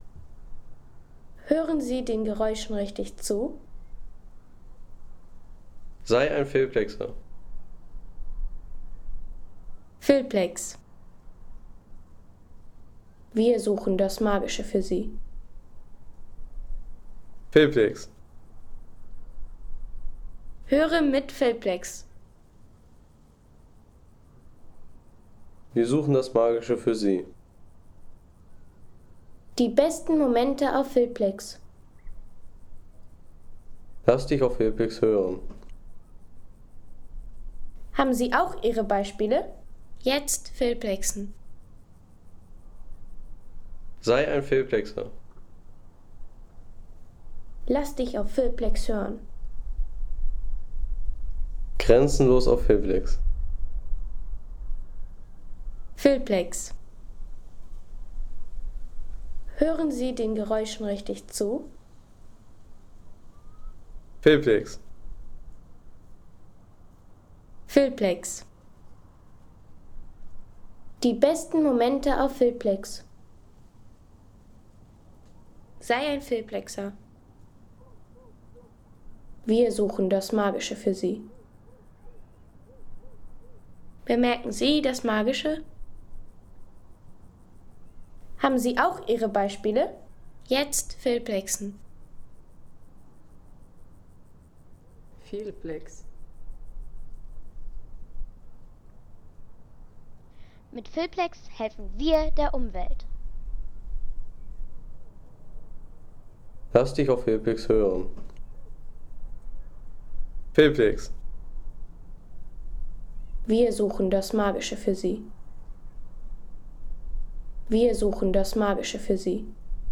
Montofarno Granitberg - Tier- & Naturgeräusch MP3 – Download für Medien & Technik | Feelplex
Wanderausflug zum Montorfano.